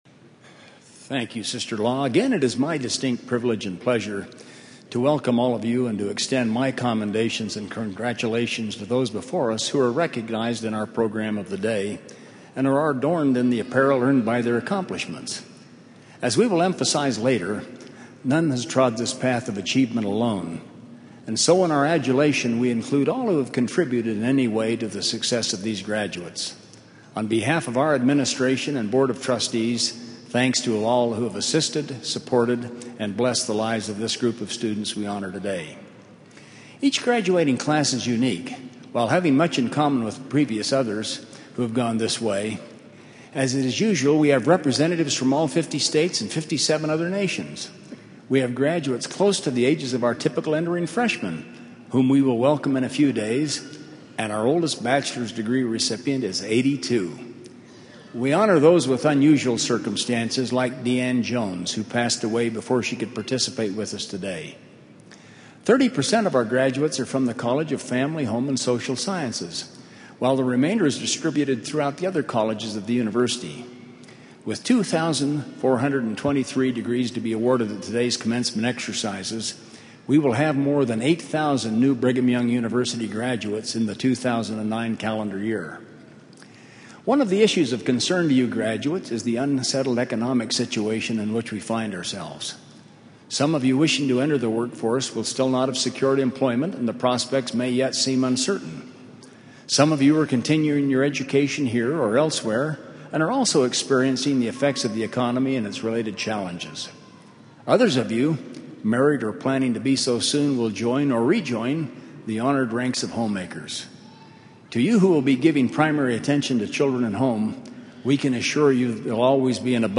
Commencement